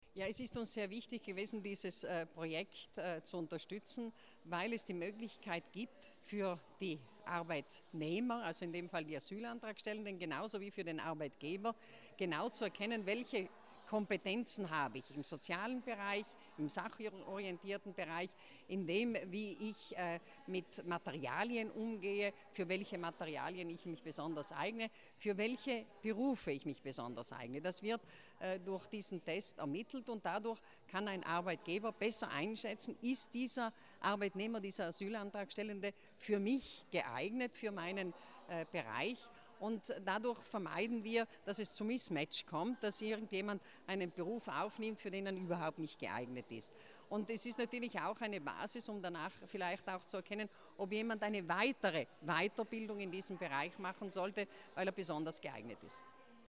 Interview Martha Stocker